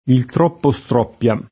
il tr0ppo Str0ppLa]); altri etimi, quali meno definibili e quali favorevoli a un’originaria vocale aperta (restando da spiegare l’eventuale chiusura successiva)